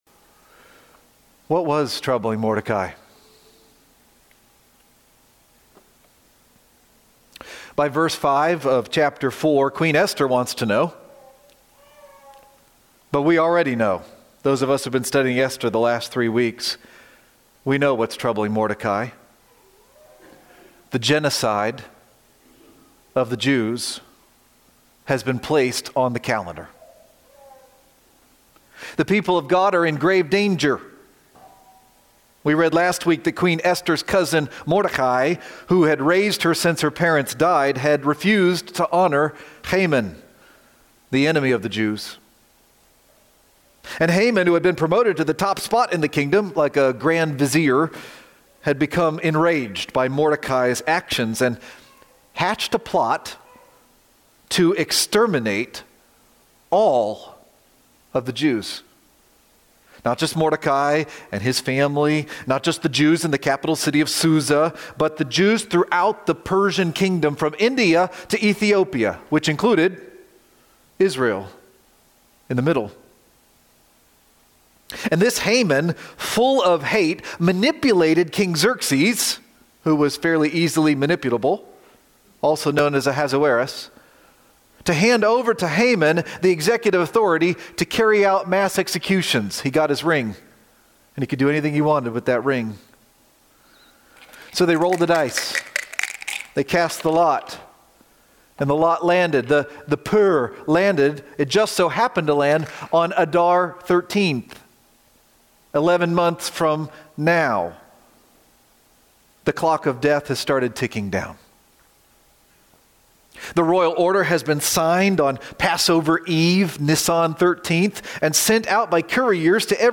Who Knows? :: November 9, 2025 - Lanse Free Church :: Lanse, PA